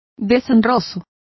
Complete with pronunciation of the translation of disgraceful.